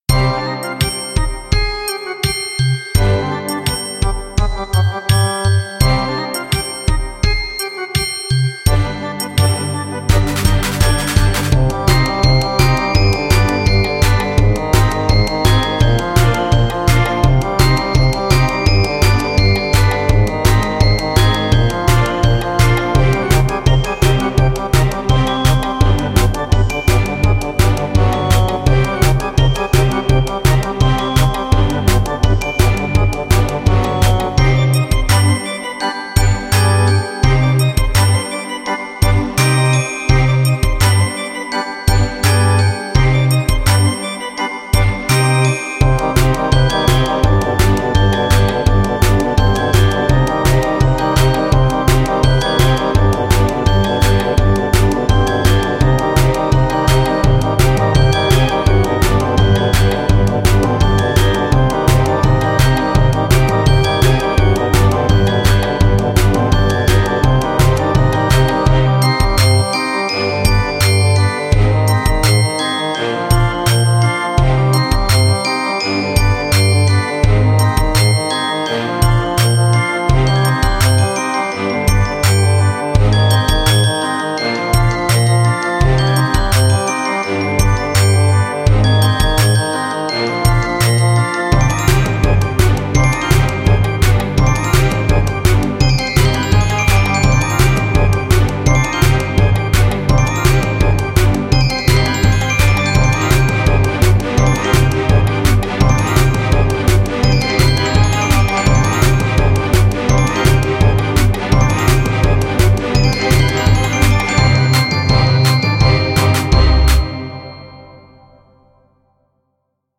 へにゃへにゃした雰囲気の曲
[BPM:120]